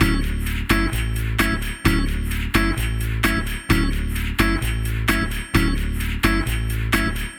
Bossa Lo Fi Tip 065-D.wav